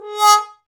Index of /90_sSampleCDs/Roland L-CDX-03 Disk 2/BRS_Trombone/BRS_TromboneMute